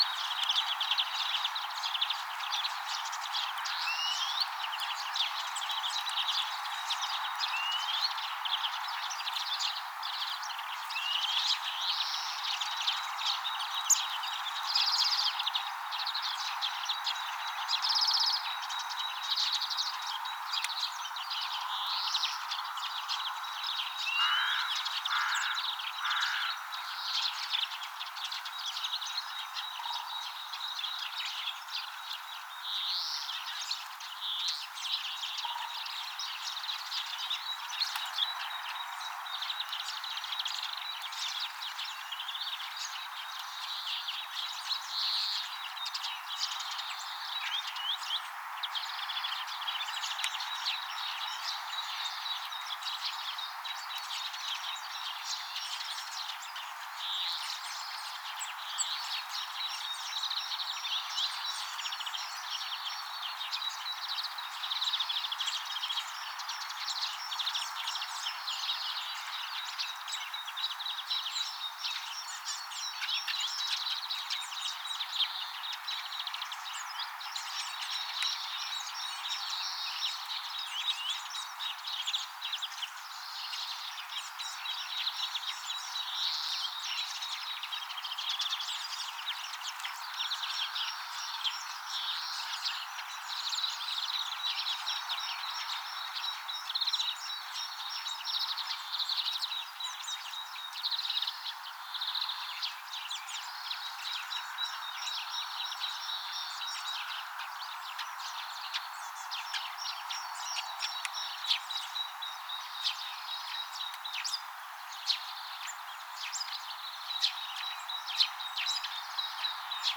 linturuokinnalla innokas tunnelma?
viherpeippo laulaa
Siinäkin yhdessä vaiheessa kuului erikoisia
Ääntelijäsinitiainen oli kyllä ihan lähellä silloin.
viherpeippo_laulaa_paljon_lintujen_innokkaita_aantelyja.mp3